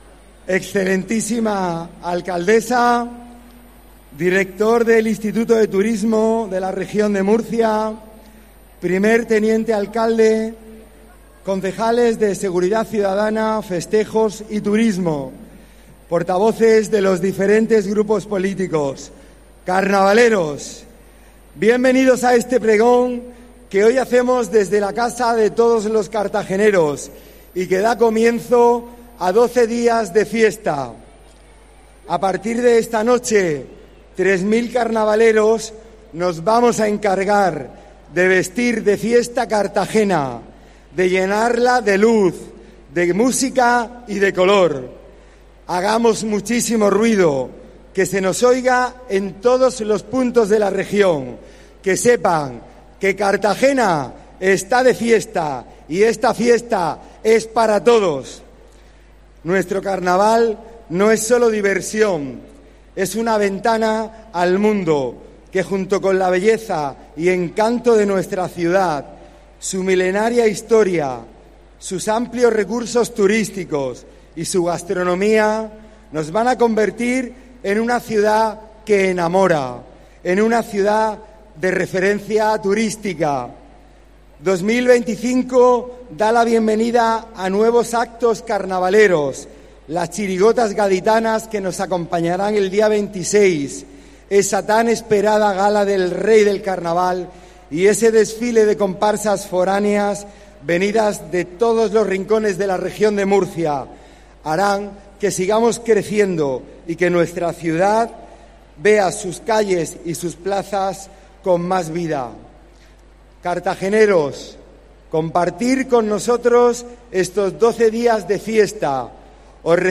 Arropado por cientos de personas y al son de los tambores de la batucada, el pregonero ha tomado la palabra desde el balc�n del Palacio Consistorial augurando la declaraci�n de Inter�s Tur�stico Nacional para las fiestas
Intervenciones